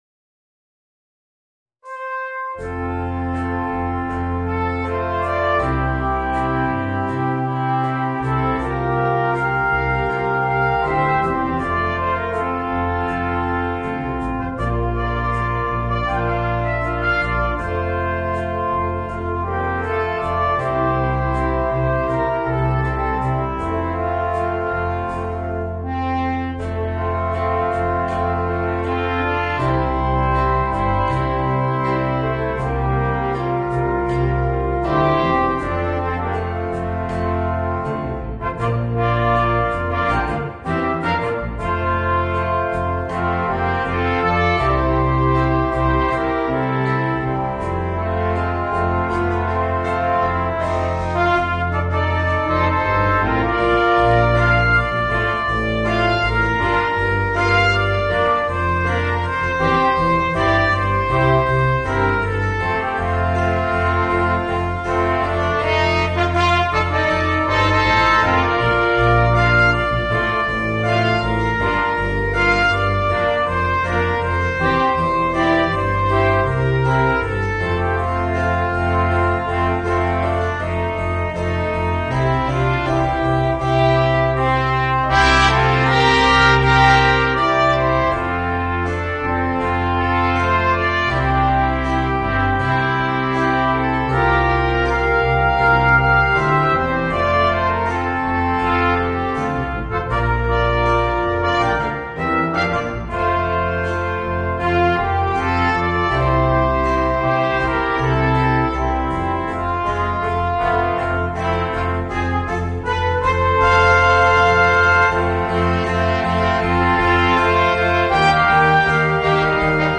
Voicing: Small Ensembles